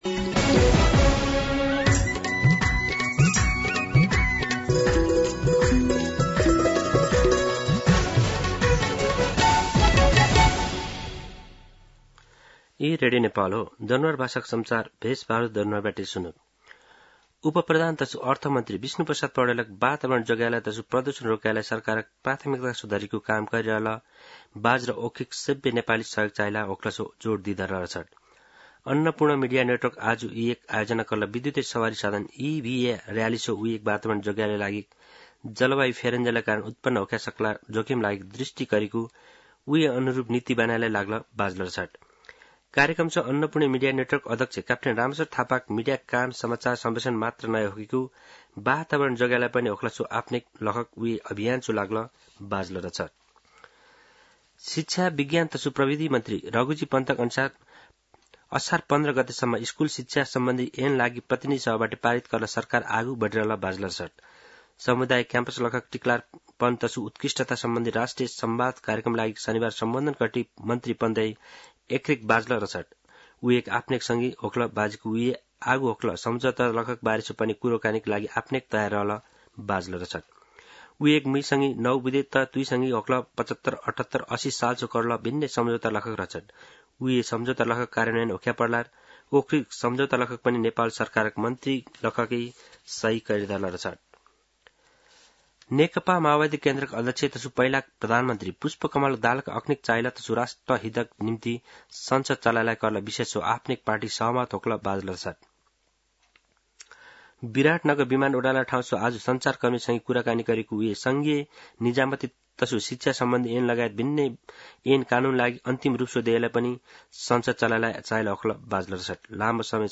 दनुवार भाषामा समाचार : ३१ जेठ , २०८२
Danuwar-News-31.mp3